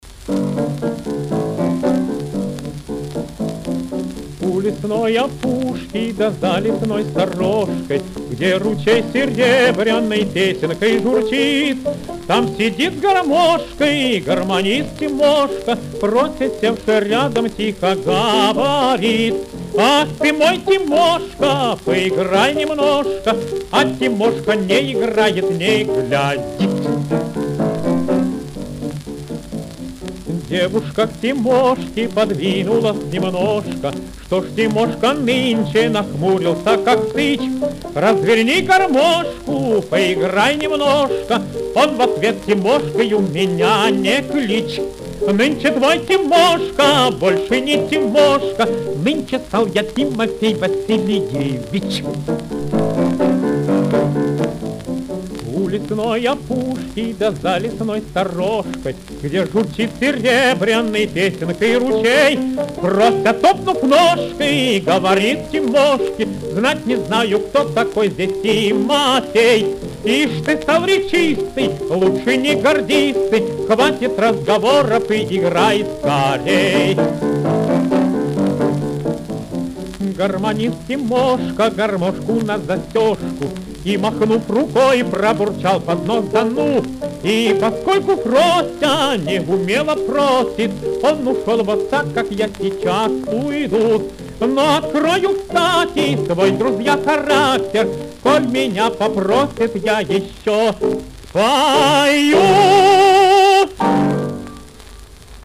Приятная песня для поднятия новогоднего настроения.
ф-но